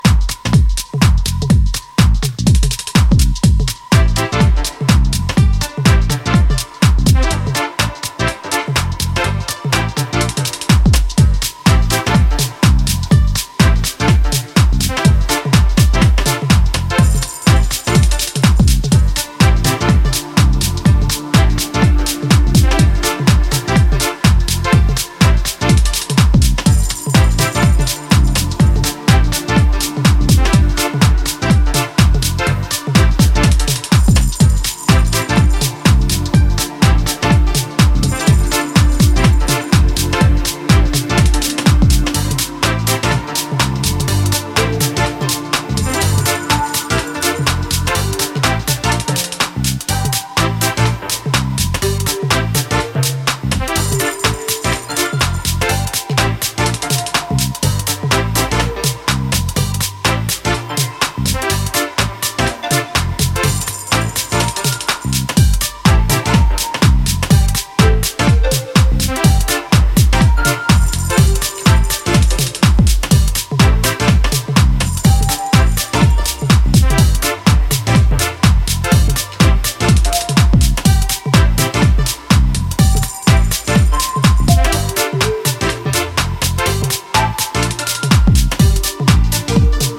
バウンスぎみのベースに気持ち良いメロディアスなシンセサイザーに魅了されるスペシャルなトラック・ワークは流石！
ジャンル(スタイル) DEEP HOUSE